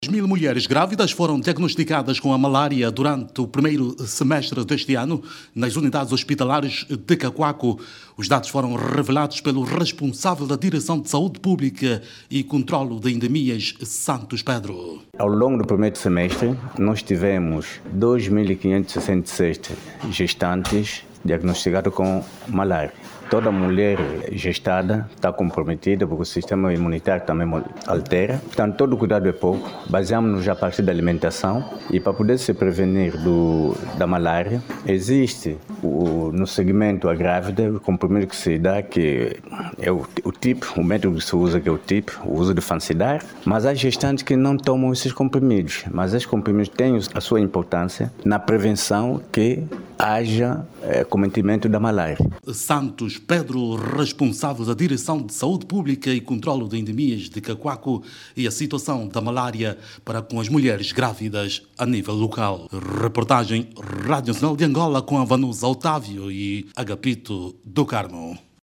RADIO-CACUACO-MALARIA-06HRS.mp3